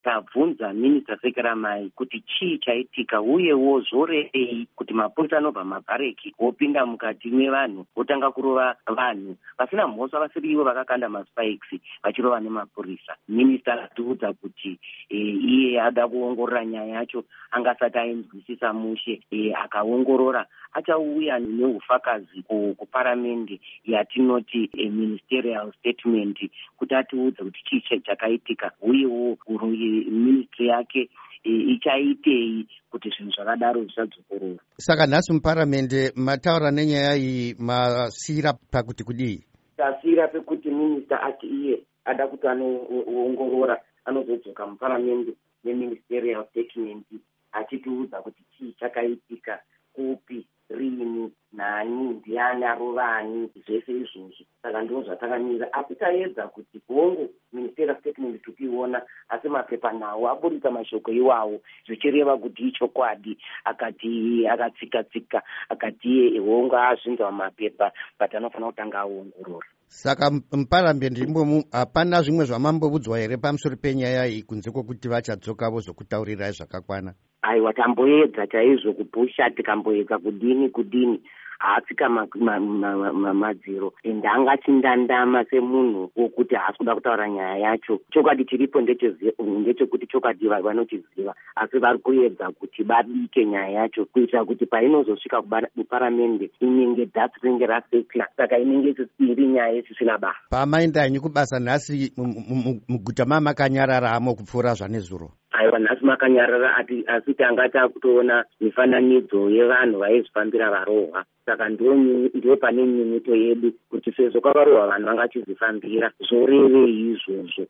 Hurukuro naMuzvare Thabitha Khumalo